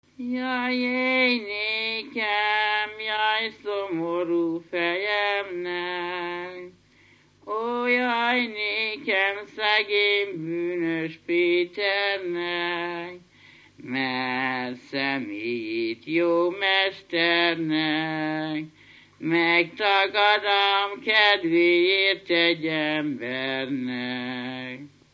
Dunántúl - Szerém vm. - Kórógy
Stílus: 5. Rákóczi dallamkör és fríg környezete
Kadencia: 4 (4) b3 1